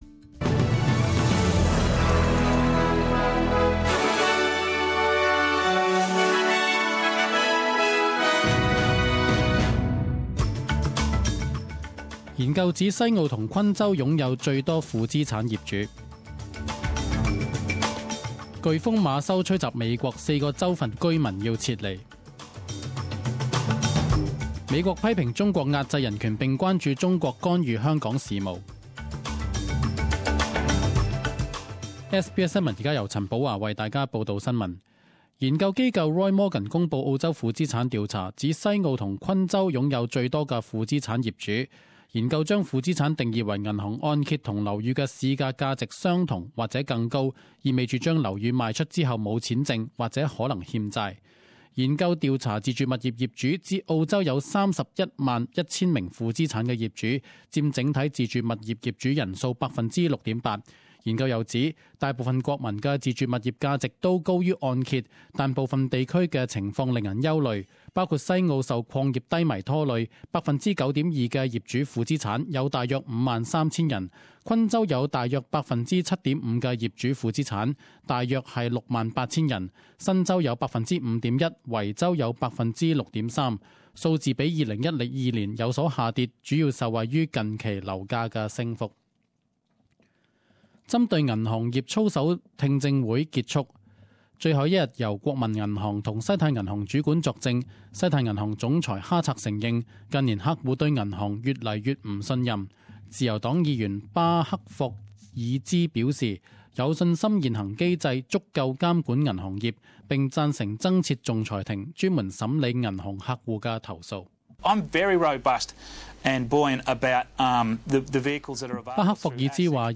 10am News Bulletin 07.10.2016